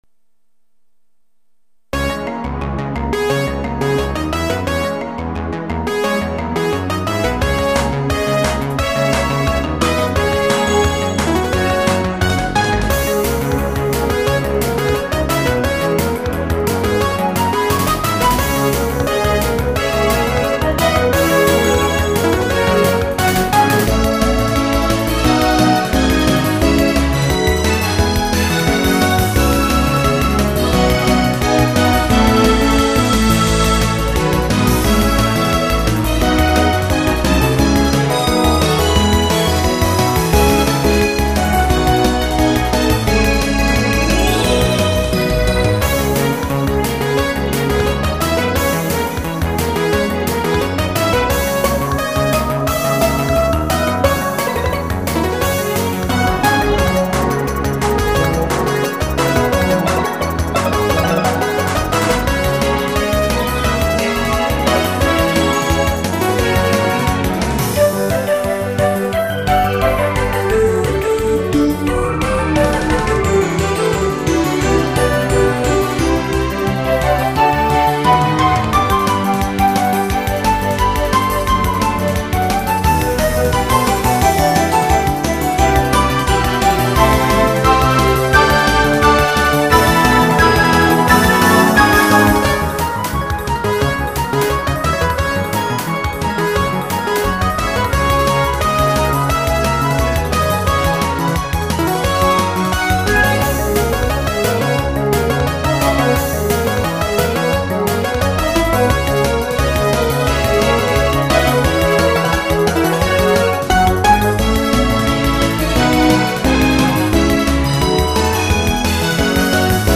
大変ごちゃごちゃしてます。勇ましい雰囲気。